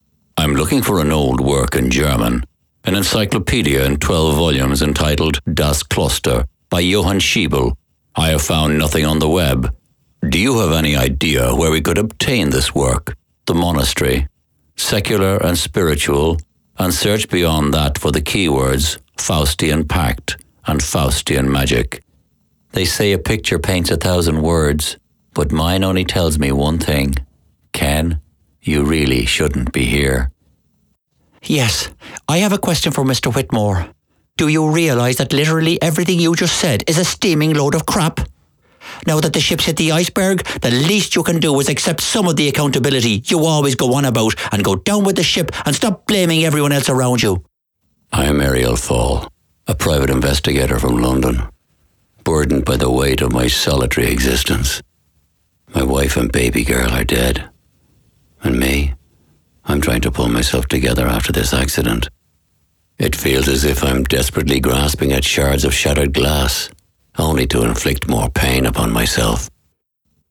English (Irish)
Audiobooks
🎙 Warm, authentic Irish voice with depth, clarity and versatility
• Broadcast-quality sound